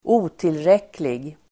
Uttal: [²'o:tilrek:lig]